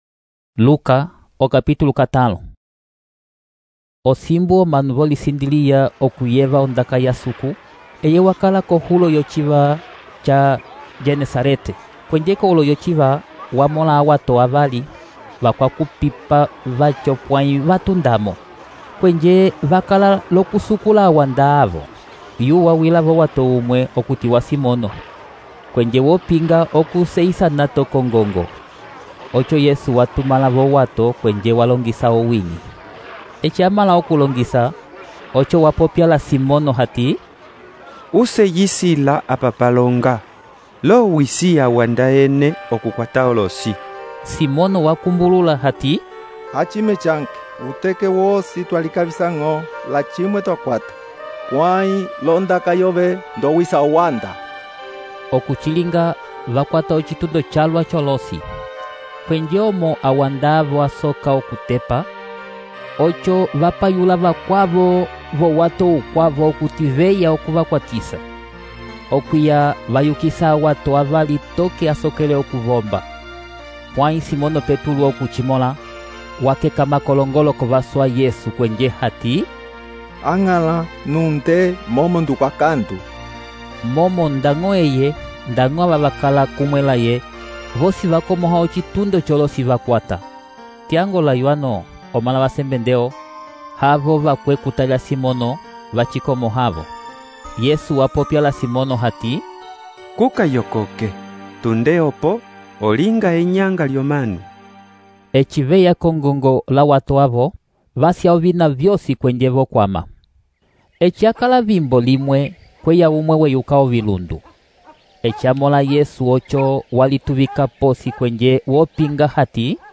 EMBIMBILIYA LIKOLA- Narração em áudio: A pesca maravilhosa, os primeiros discípulos.